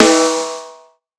West MetroSnare (2).wav